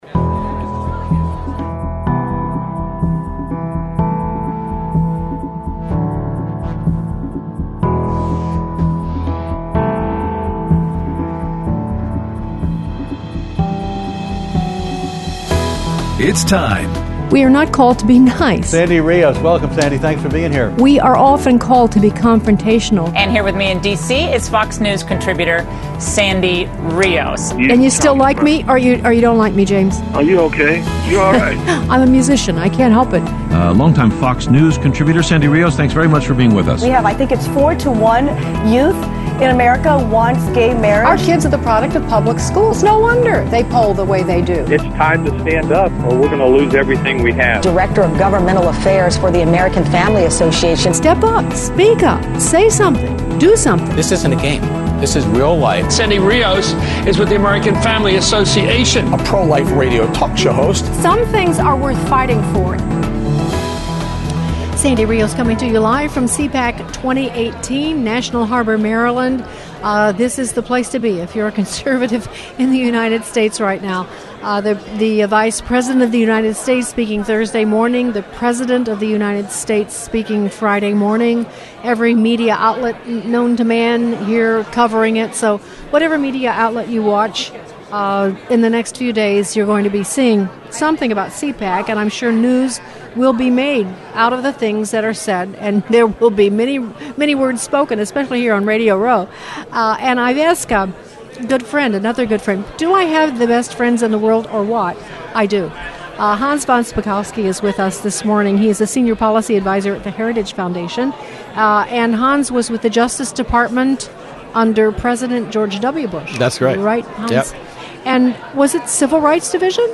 Interviews from CPAC 2018